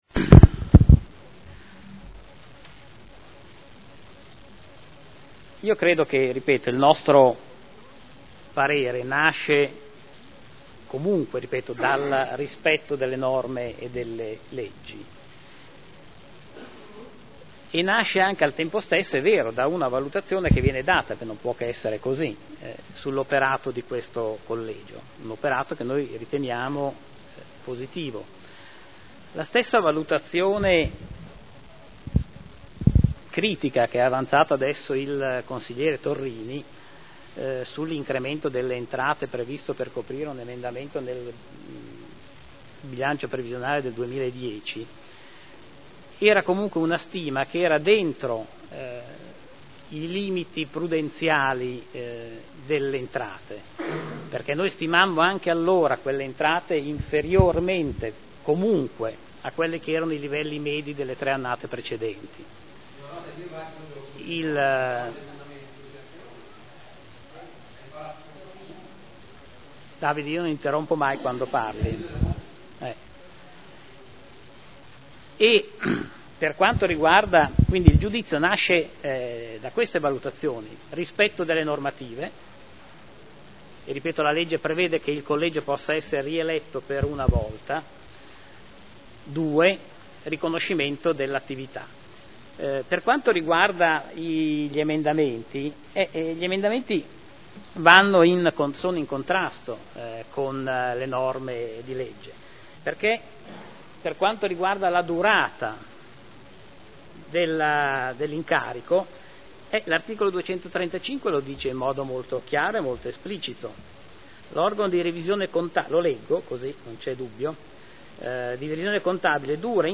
Seduta del 12/03/2012. Replica a dibattito su Delibera: Rielezione del Collegio dei Revisori dei conti per il triennio 2012 - 2014 (Conferenza Capigruppo del 23, 30 gennaio e 5 marzo 2012)